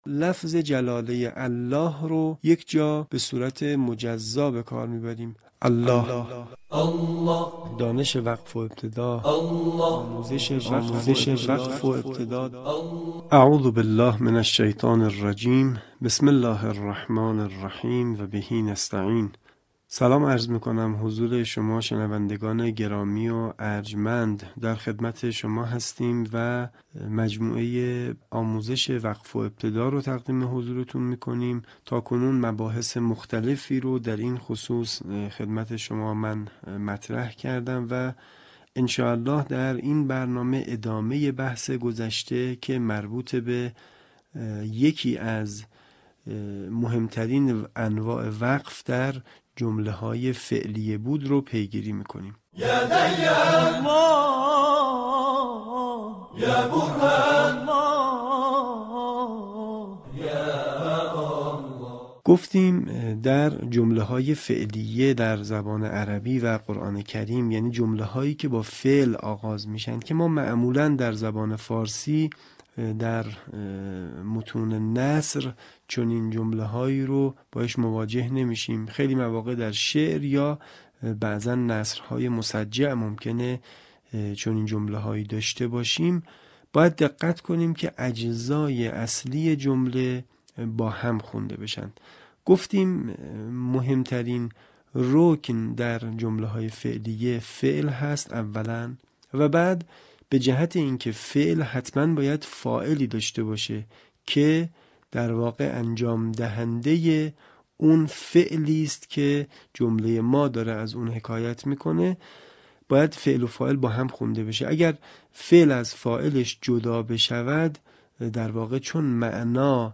آموزش وقف و ابتدا